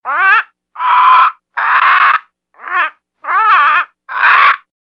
Tiếng Vẹt Kêu (Nhạc Chuông)